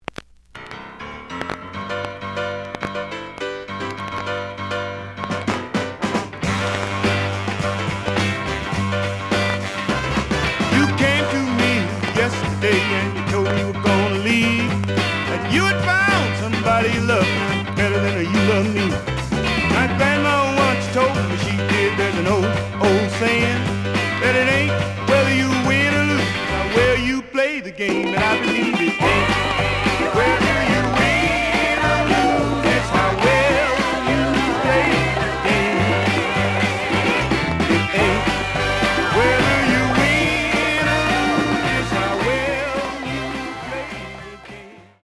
The audio sample is recorded from the actual item.
●Genre: Soul, 70's Soul
Some periodic noise on B side due to deep scratches.